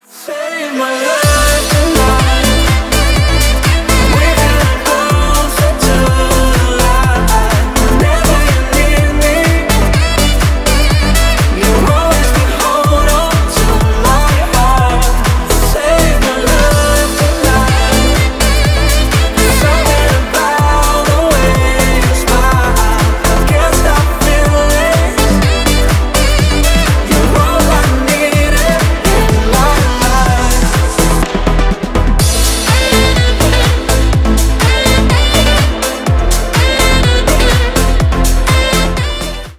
Ремикс # Поп Музыка
клубные